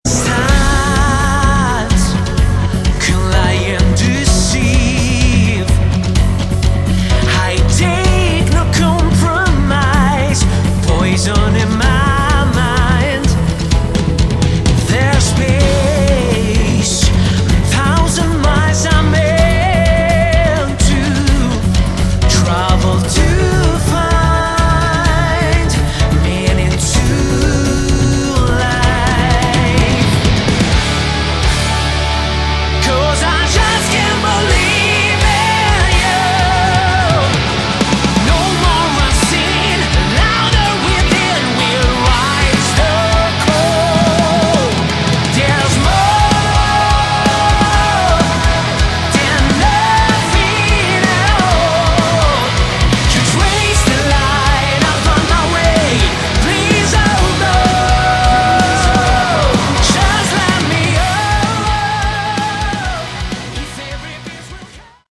Category: Progressive Rock/Metal
lead vocals
guitars
bass
keyboards
drums